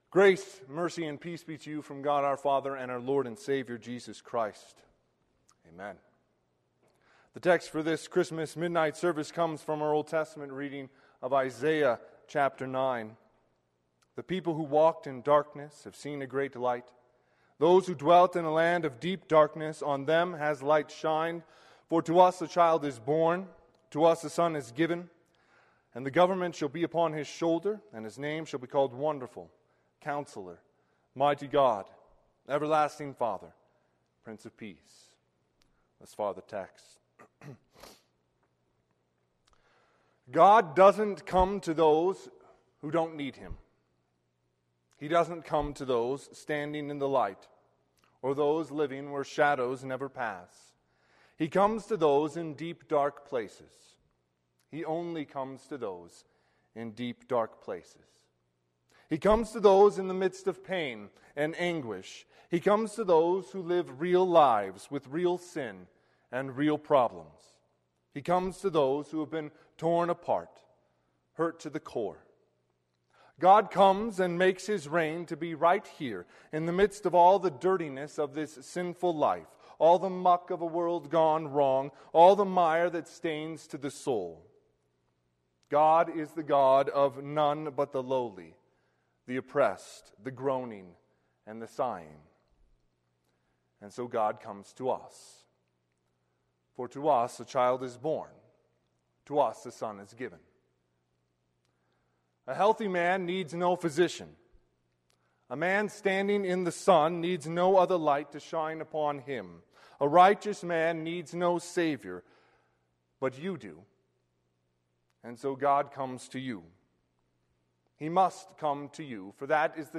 Sermon - 12/24/2020 - Midnight - Wheat Ridge Lutheran Church, Wheat Ridge, Colorado
Christmas Eve Midnight Service
Sermon_Dec24_Midnight.mp3